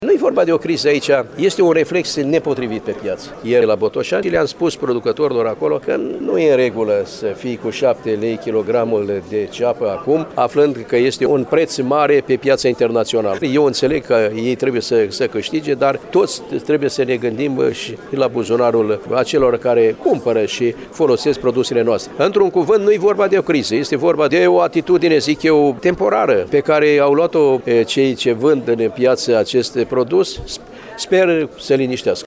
Tot la întîlnirea cu fermierii din judeţul Iaşi, ministrul Agriculturii, Petre Daea, a afirmat că, pe pieţele agro-alimentare din România nu există o criză a cepei, iar creşterile de preţuri din aceste zile reprezintă un reflex nepotrivit al agricultorilor.